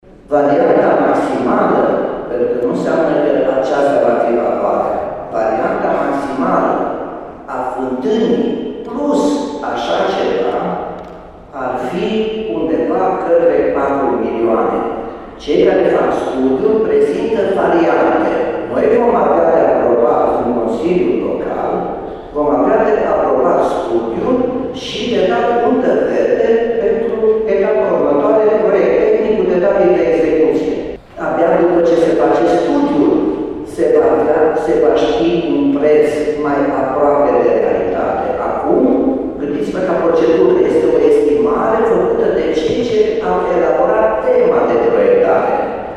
La rândul său, primarul Timișoarei, Nicolae Robu, a declarat la dezbaterea publică faptul că suma de patru milioane de euro este doar una estimativă.